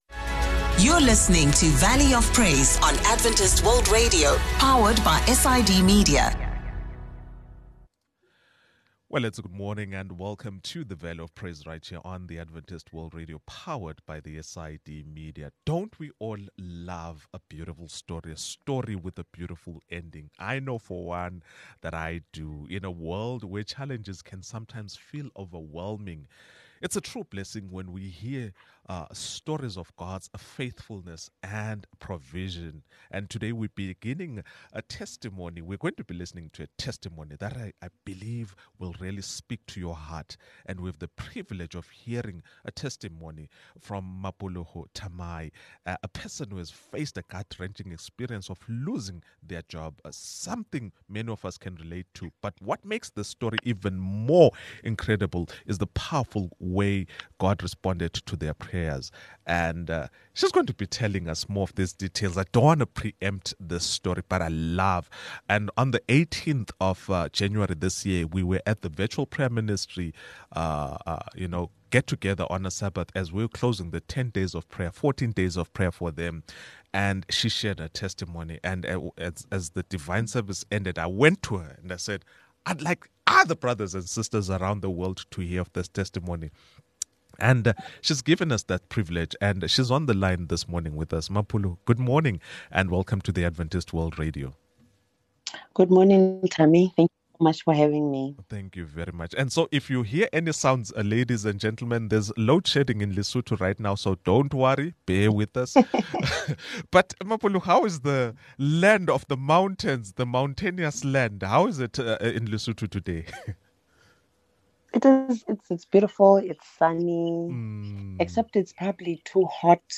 Join us as we hear an inspiring testimony of faith, perseverance, and divine timing. Our guest shares the powerful story of losing their job and how they faced the uncertainty with prayer and trust in God's plan. After a year of waiting, God answered their prayers in an unexpected and life-changing way.